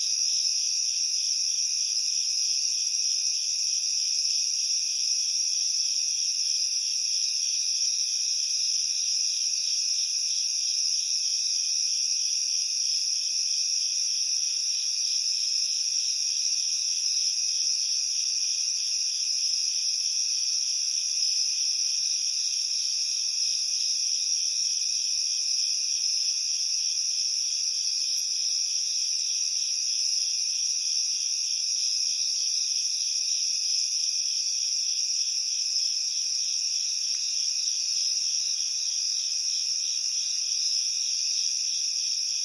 随机的 " 蟋蟀的夜晚，沉重的后院2
Tag: 蟋蟀 后院